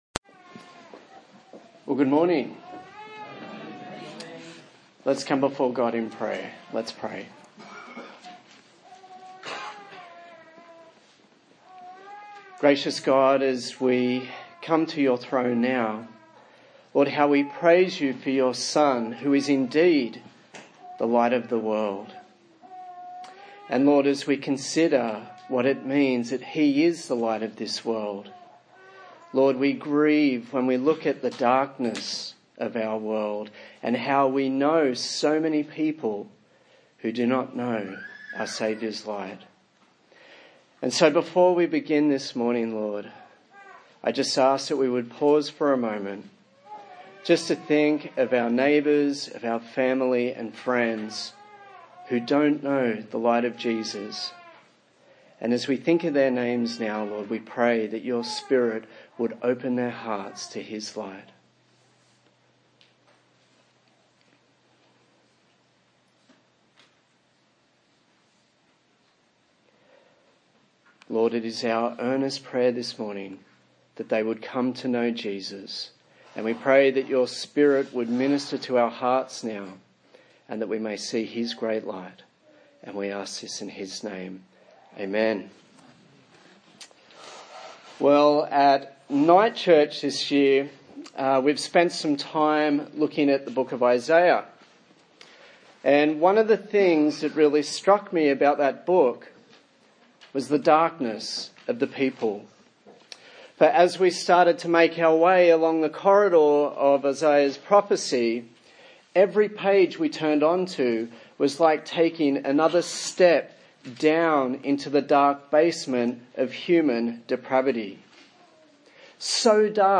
John Passage: John 8:12-30 Service Type: Sunday Morning A sermon in the series on the book of John